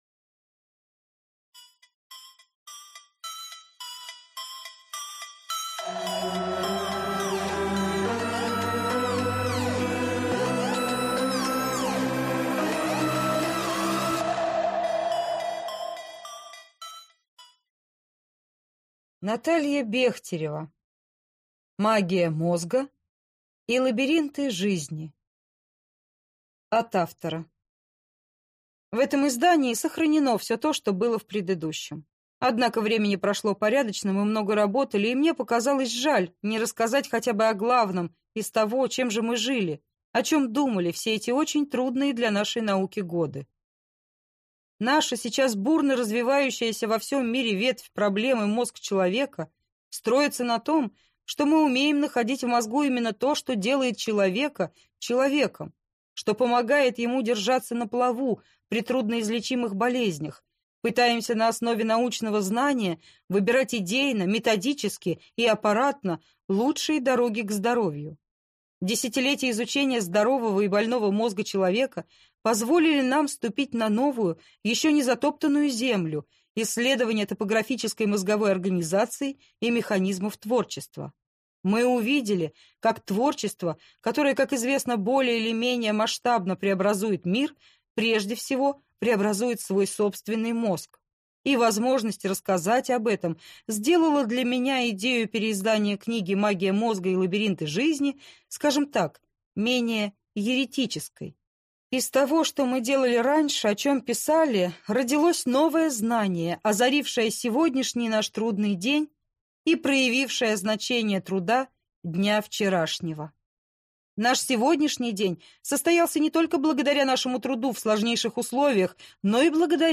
Аудиокнига Магия мозга и лабиринты жизни | Библиотека аудиокниг